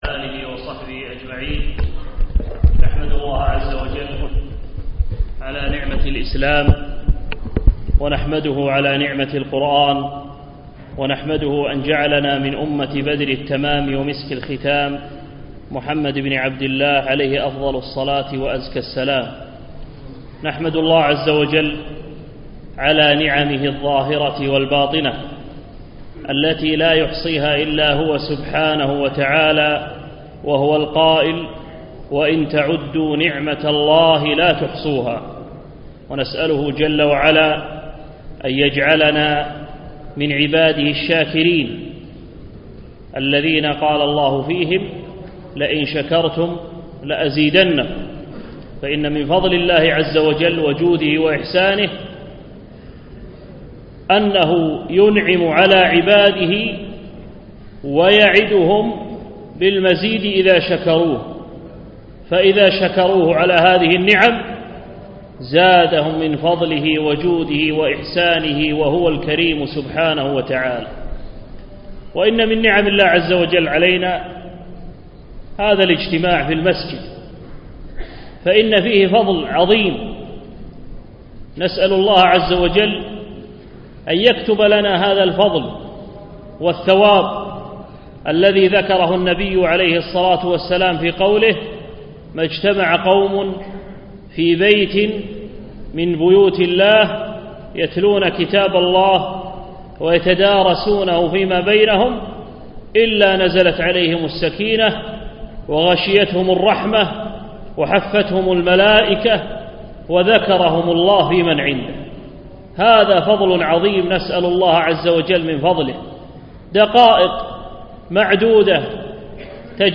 يوم الخميس 18 رجب 1436 الموافق 7 5 2015 بمسجد فهد سند العجمي خيطان